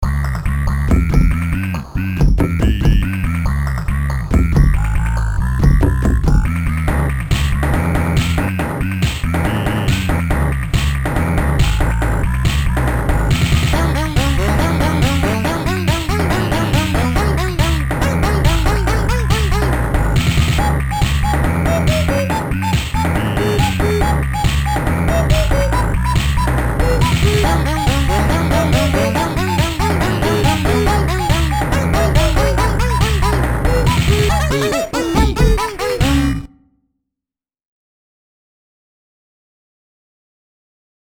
Hearing this masterpiece promped me to write my own KNP samples piece of shit music.
It is pretty KNP, though (and very reminiscent of a few themes from Aquaria in the best of ways).